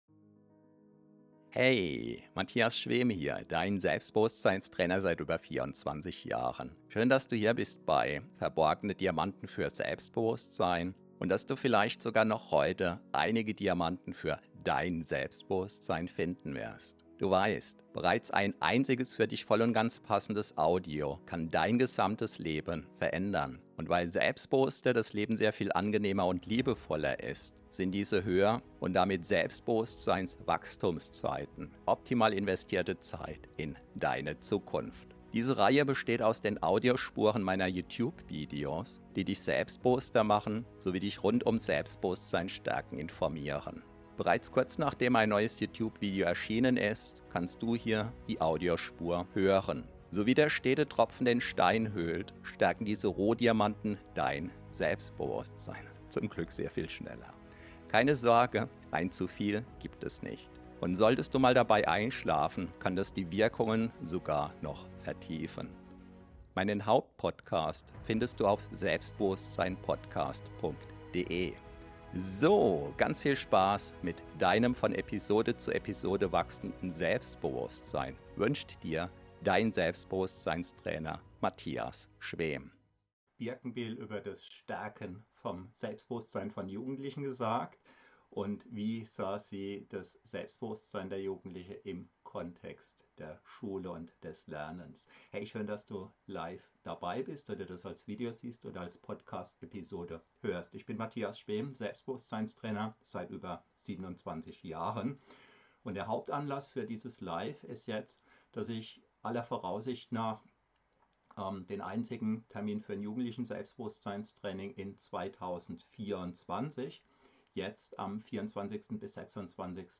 In diesem Livestream teile ich wertvolle Einblicke aus meiner 27-jährigen Praxis als Selbstbewusstseinstrainer und gebe praktische Empfehlungen, wie Jugendliche ihr Selbstbewusstsein stärken können. Das Wesentliche: Tipps und Erfahrungen für starkes Selbstbewusstsein bei Jugendlichen.